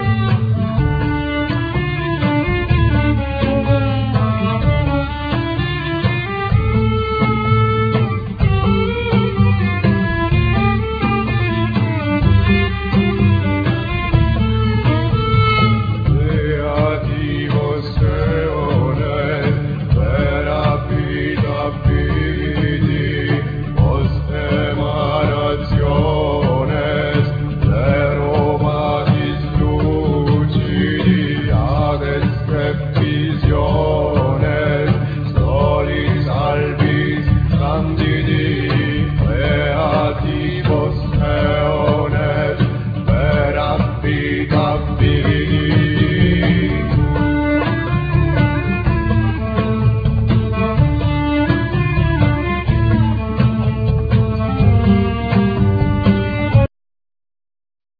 Guitar,Clarinetto,Oboe,Flute,Tamburo,Vocal
Tres cumano,Tammorra a sonagli,Chorus
Percussions,Sonagli,Timpani,Chorus
Darbouka,Chorus
Violin,Chorus